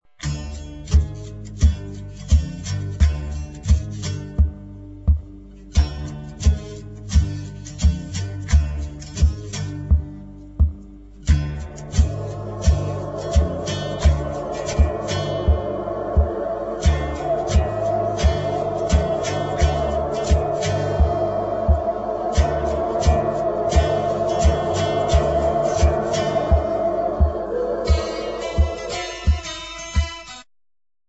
exciting medium instr.